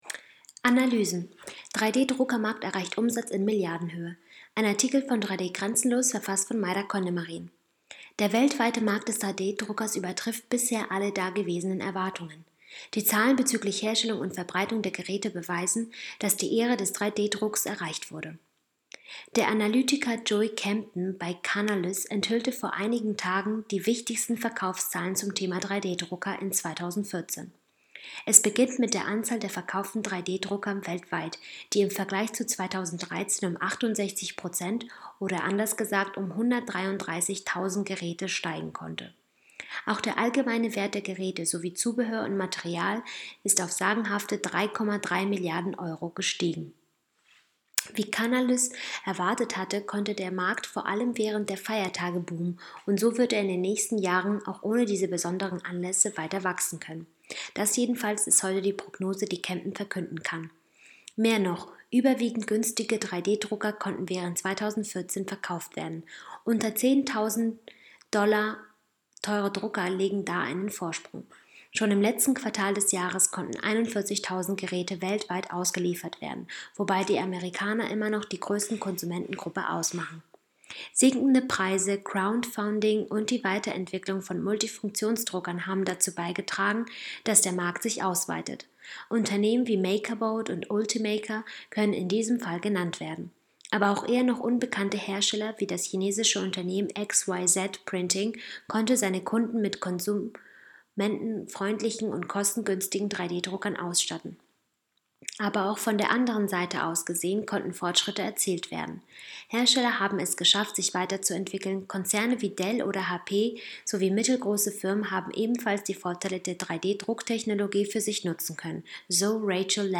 news_vorlesen_lassen_3d-druckermarkt_erreicht_umsatz_in_milliardenhoehe.mp3